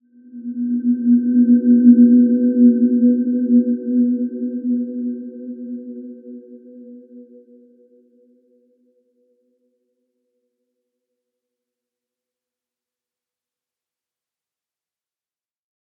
Dreamy-Fifths-B3-mf.wav